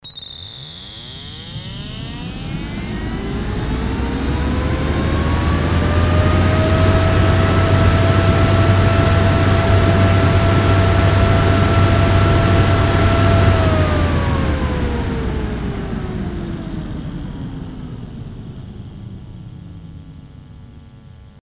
TMD fan
TMD fans didn't really do anything special besides make a bitchin' noise when
miced too close, and they no doubt cost a lot more to make, so I guess they just died out.